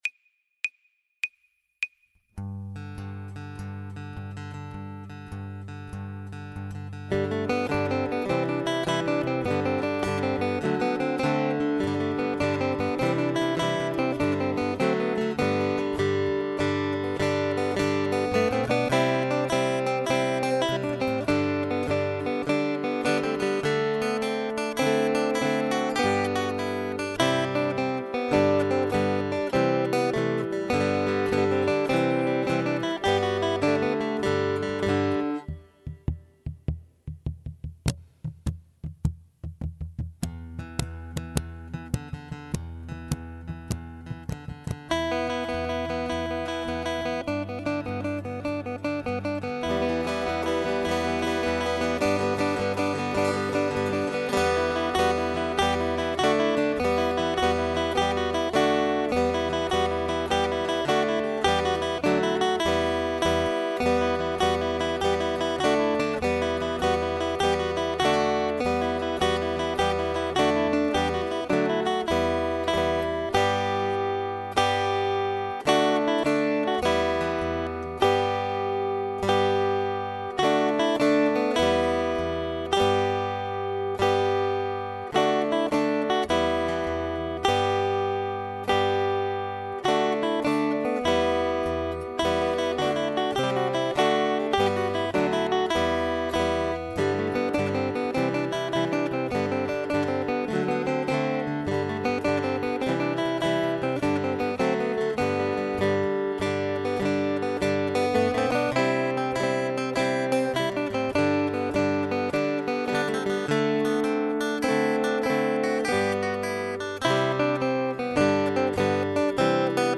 guitar ensemble arrangements